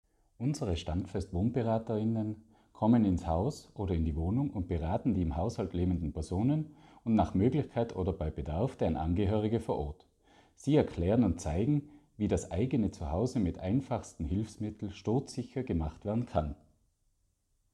O-Ton